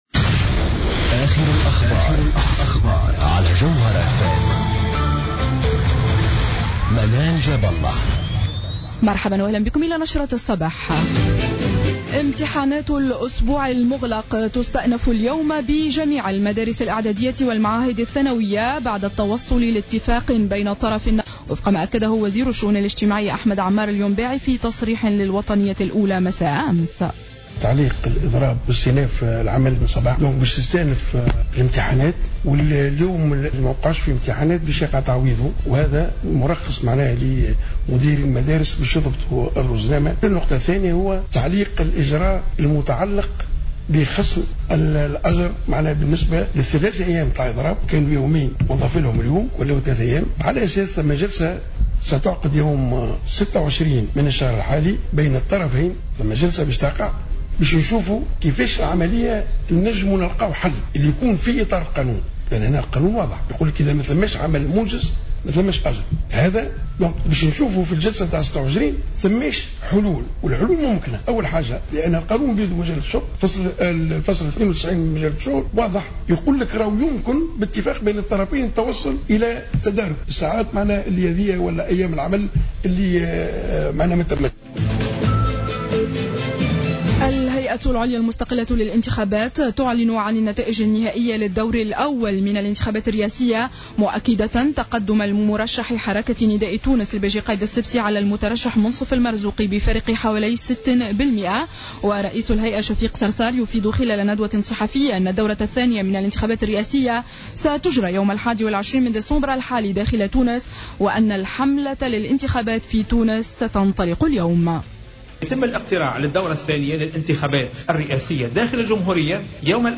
نشرة أخبار السابعة صباحا ليوم 09-12-14